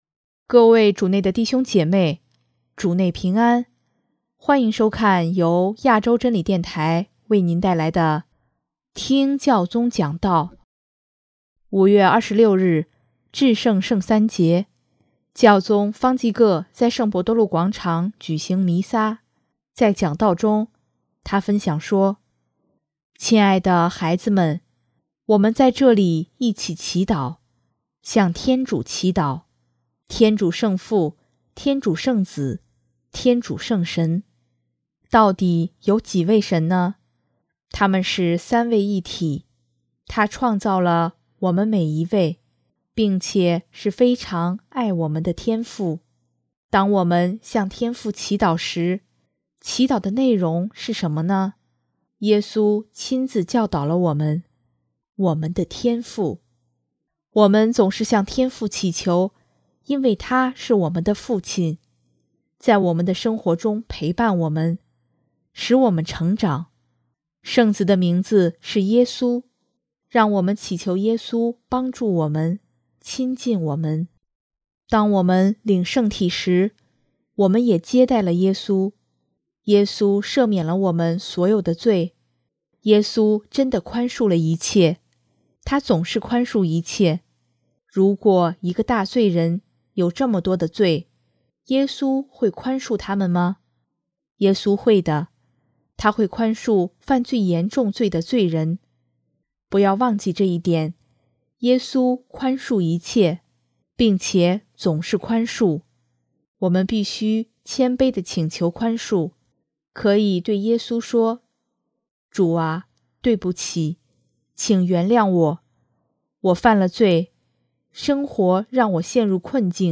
5月26日，至圣圣三节，教宗方济各在圣伯多禄广场举行弥撒，在讲道中，他分享说：